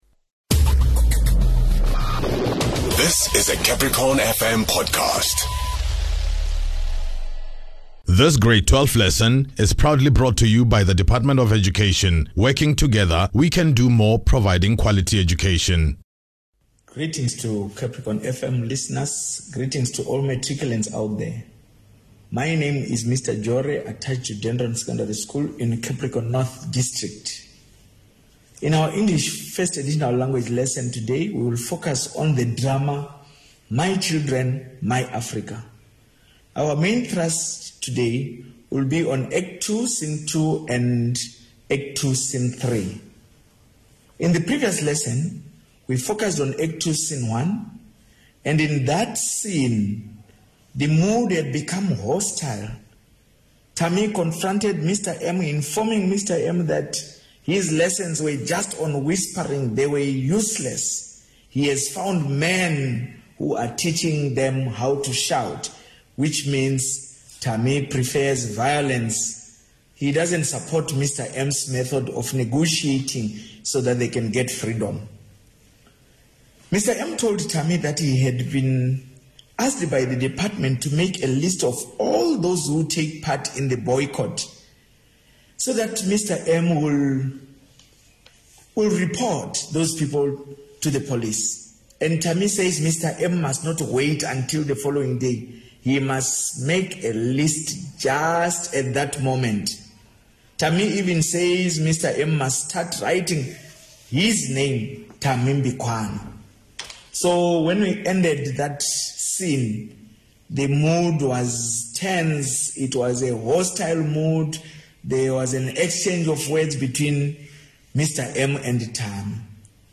As the year edges to an end, the Limpopo Department of Basic Education has dedicated time everyday on CapricornFM to helping Grade12 learners catch up on various lessons